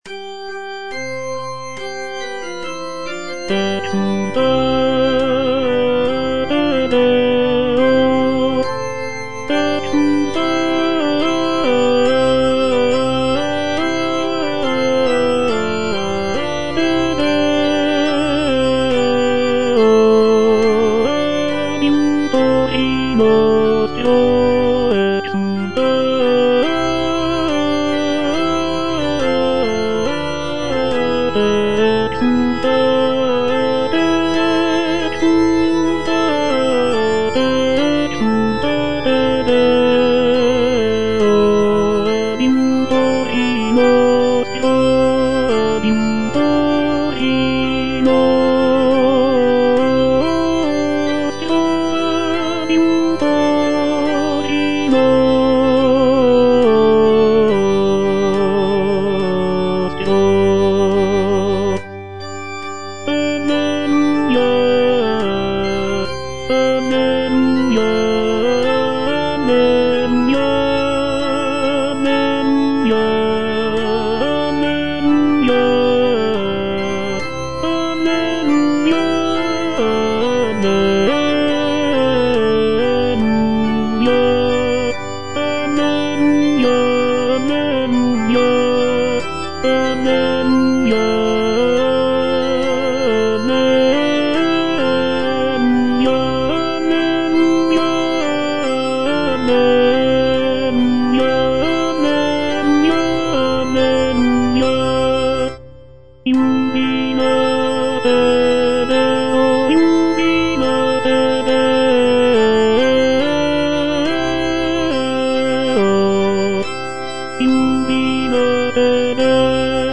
Tenor (Voice with metronome) Ads stop
sacred choral work